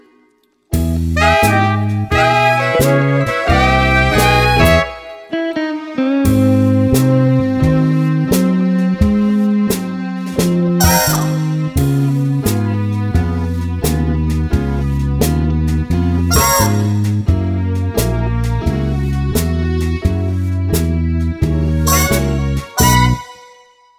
Three Semitones Up Pop